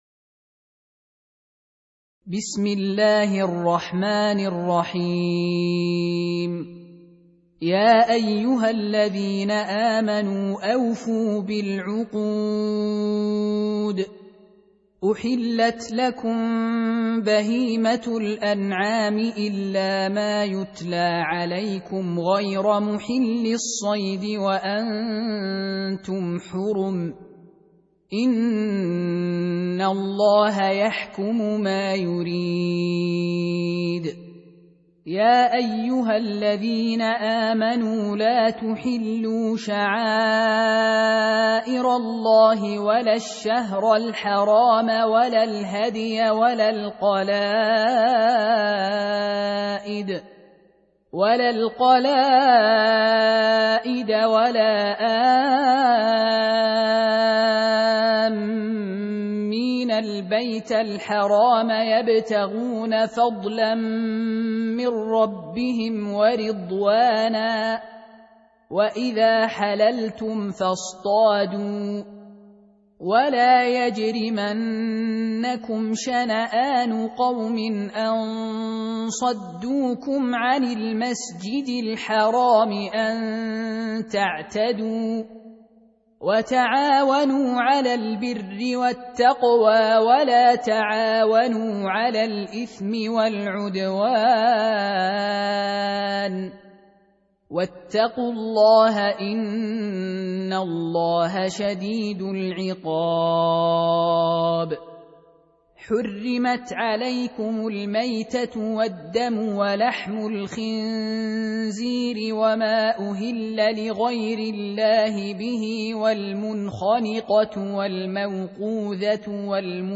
Surah Repeating تكرار السورة Download Surah حمّل السورة Reciting Murattalah Audio for 5. Surah Al-M�'idah سورة المائدة N.B *Surah Includes Al-Basmalah Reciters Sequents تتابع التلاوات Reciters Repeats تكرار التلاوات